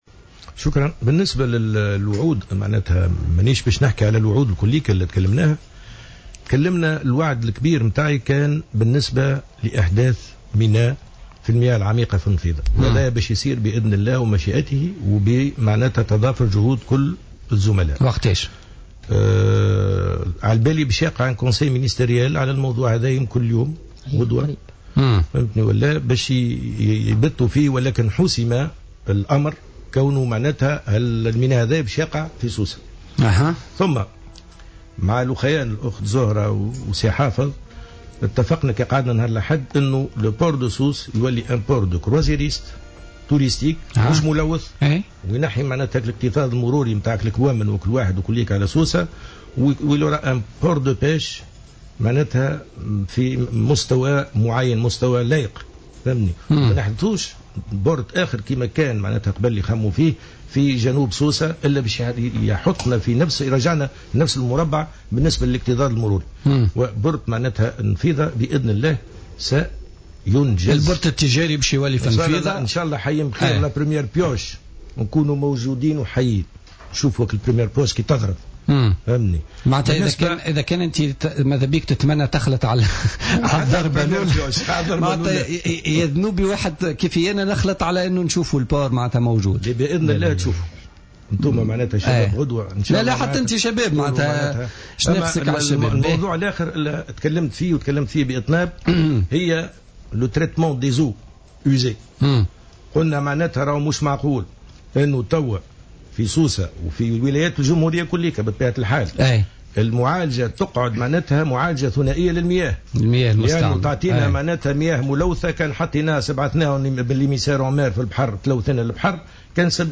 وأوضح في برنامج "بوليتيكا" على "الجوهرة أف أم" أن انجاز هذه المحطة سينطلق خلال 3 أشهر على أقصى تقدير وسيتم استخدام تقنية المعالجة الثلاثية لاستخراج مياه نظيفة تستعمل للري أساسا وما سيقلل من آثارالتلوث خاصة بالبحر والأودية.